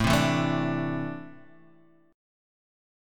A4-3 chord